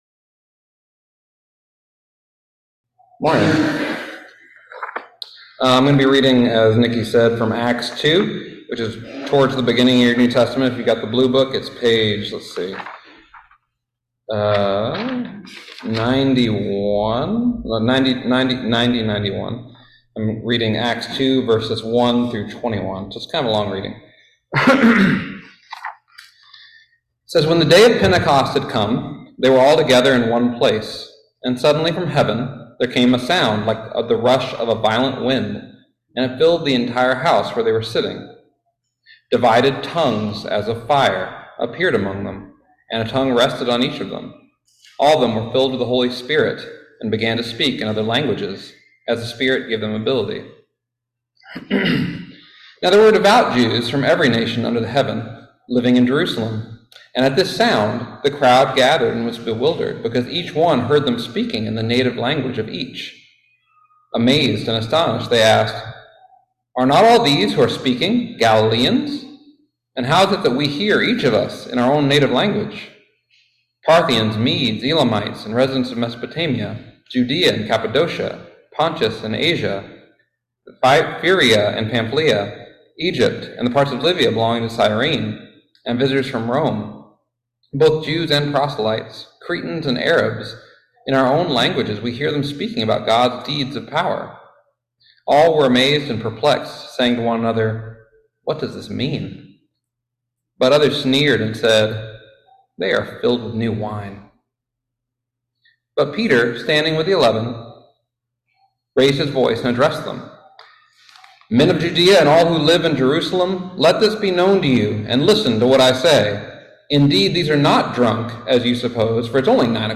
Listen to the most recent message from Sunday worship at Berkeley Friends Church, “What Does It Mean?”